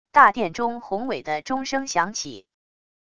大殿中宏伟的钟声响起wav音频